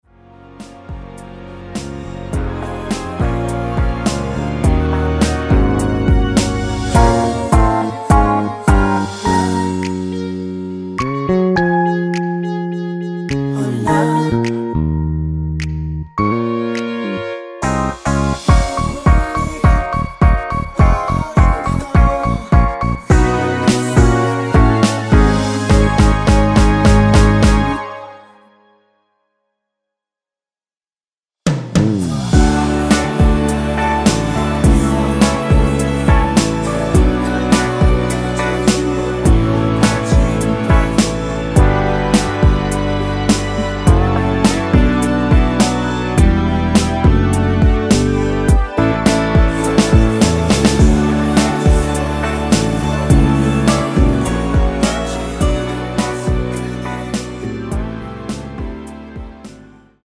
코러스 MR입니다.
원키에서(-1)내린 코러스 포함된 MR입니다.
Db
앞부분30초, 뒷부분30초씩 편집해서 올려 드리고 있습니다.